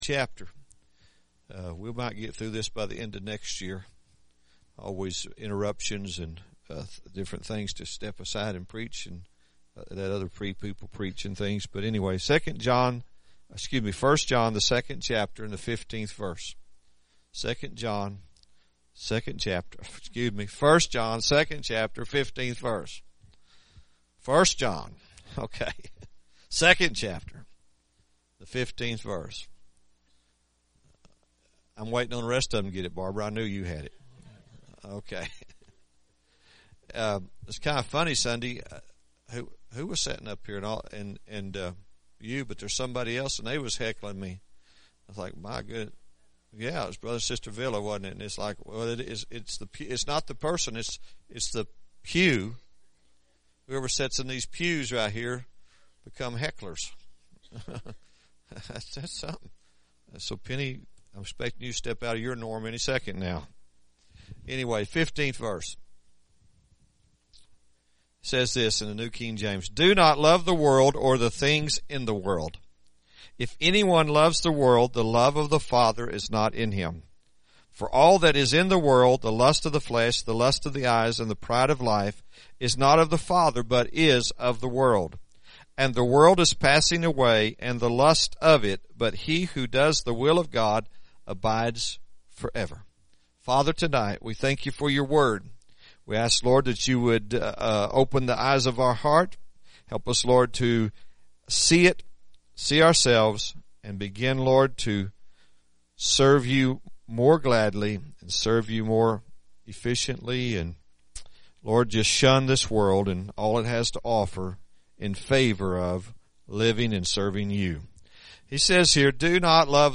1 John Series – Sermon 5